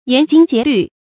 研精竭慮 注音： ㄧㄢˊ ㄐㄧㄥ ㄐㄧㄝ ˊ ㄌㄩˋ 讀音讀法： 意思解釋： 專心研究，盡力思考。